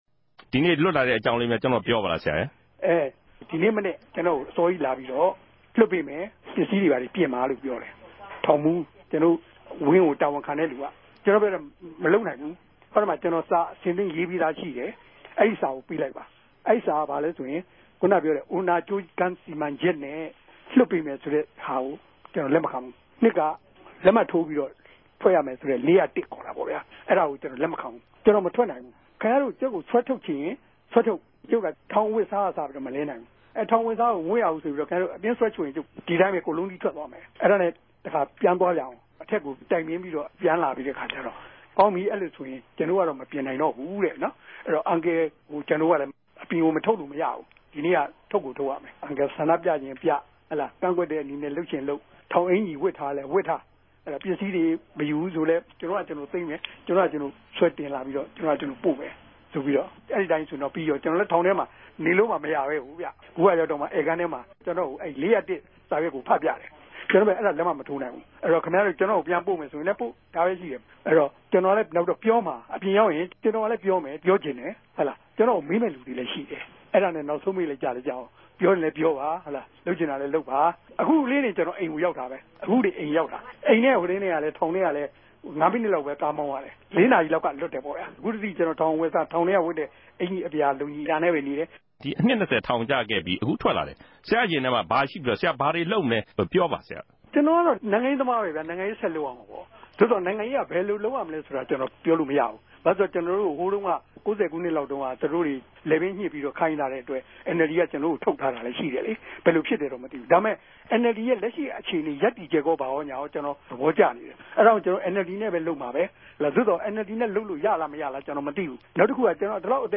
ဦးဝင်းတငိံြင့် ဆက်သြယ်မေးူမန်းခဵက်။